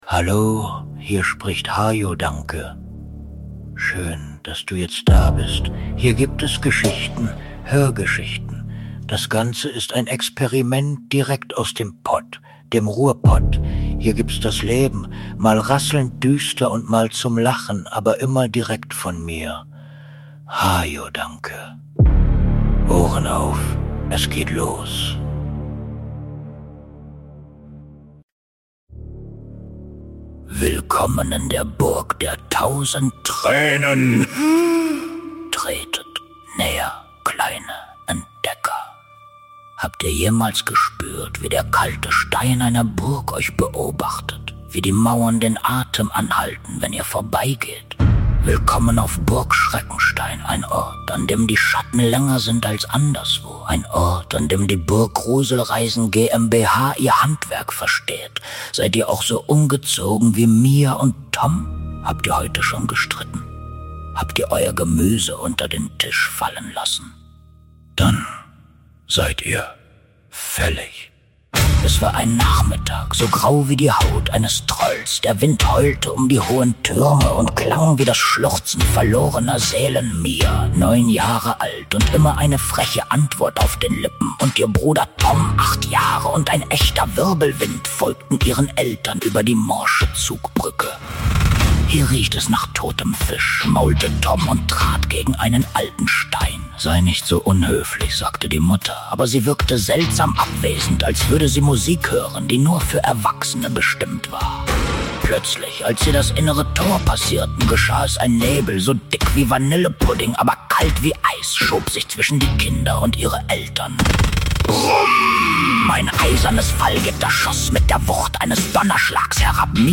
Die Burg der tausend Tränen - Grusel (Hör)Geschichten für junge Entdecker ab ca. 6 Jahren